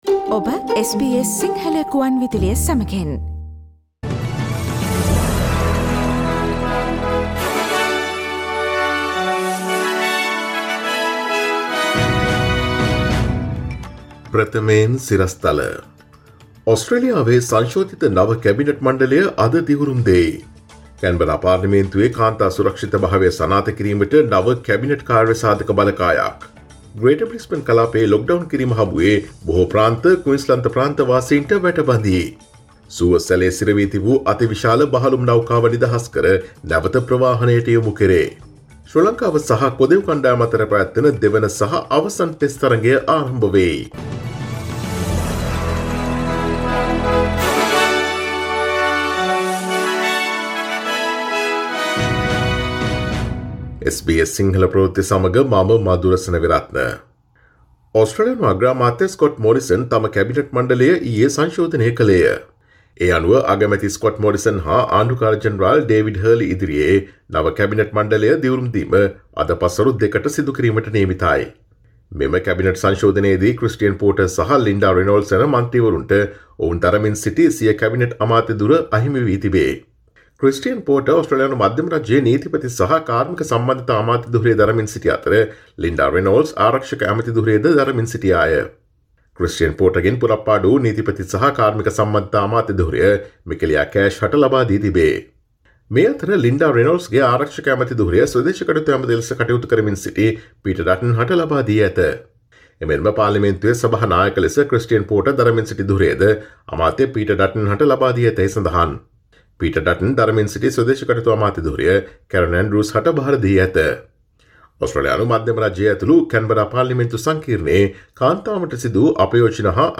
Here are the most prominent Australian, Sri Lankan, International, and Sports news highlights from SBS Sinhala radio daily news bulletin on Tuesday 30 March 2021.